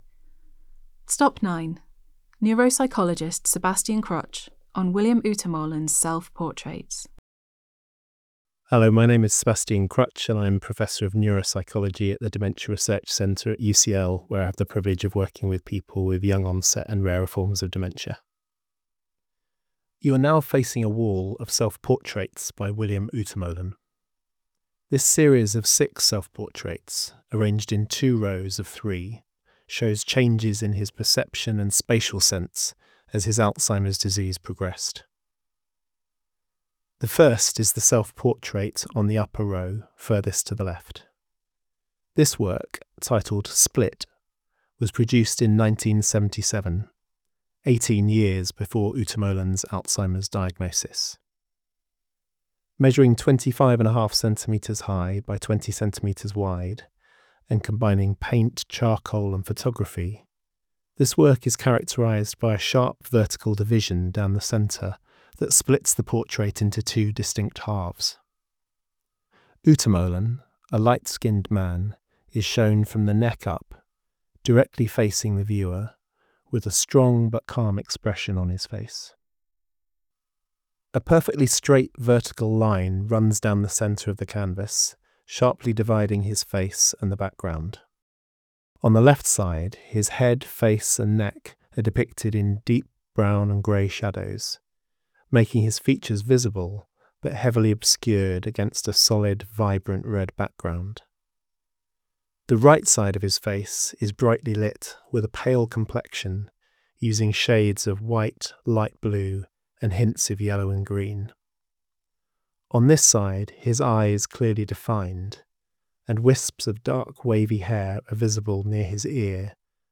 Explore our exhibitions using your own device, with audio description, British Sign Language and gallery captions